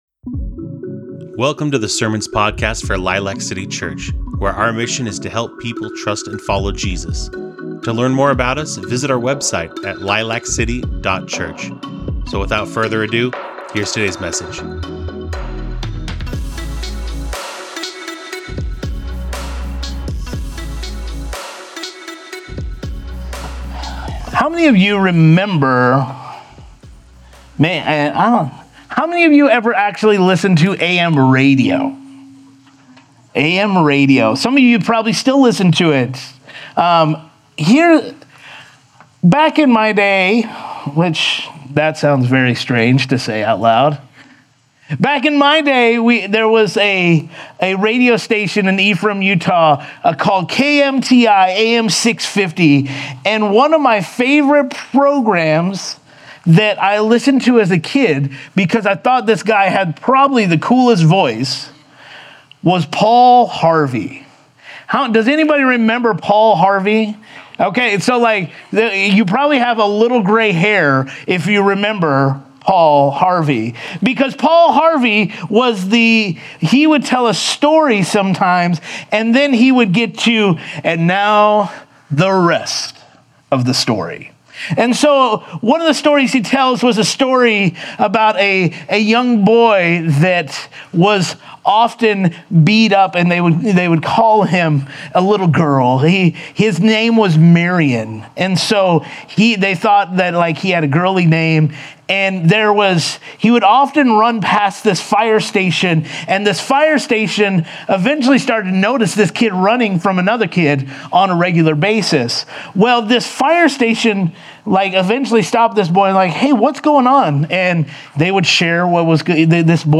Discover how faith in Jesus makes you righteous in this powerful sermon on Romans 3:21-31.